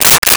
Stapler 01
Stapler 01.wav